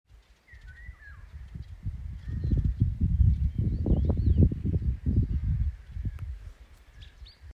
иволга, Oriolus oriolus
Ziņotāja saglabāts vietas nosaukumsDārzs
СтатусСлышен голос, крики